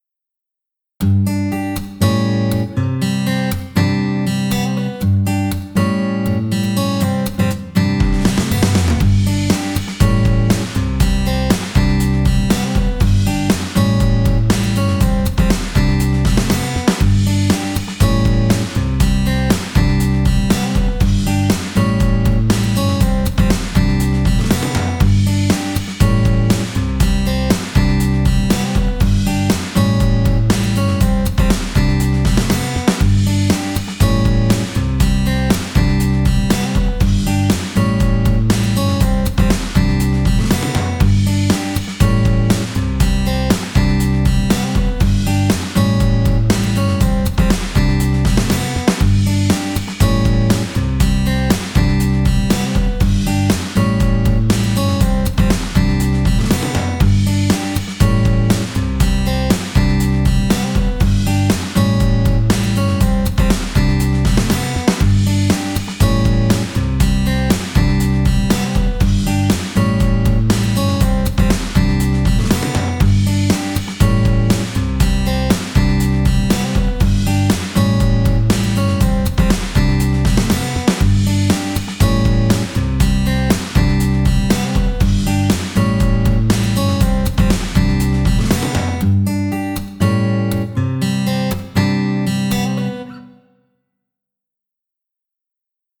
かっこよさも感じられるKawaii Future Bass BGMです。